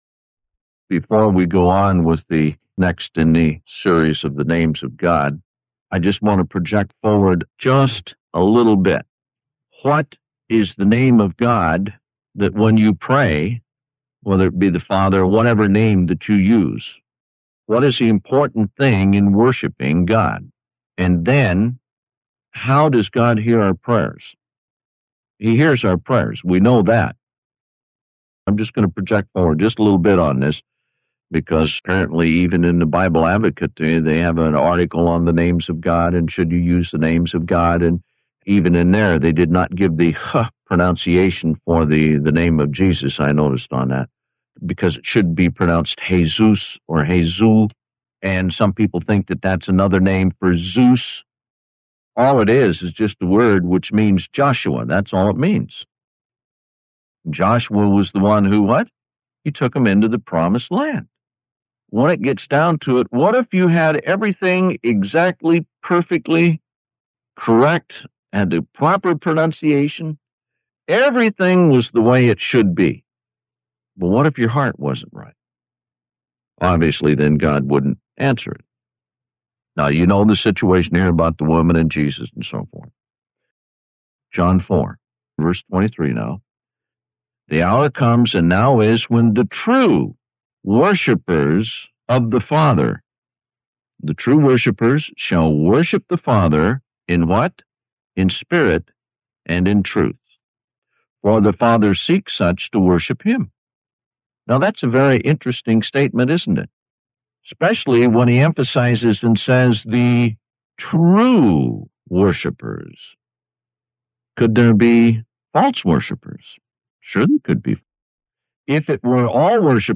Track 2 | Download | PDF - [ Up ] Before we go on with this sermon in the series of the names of God, I want to project forward a little bit.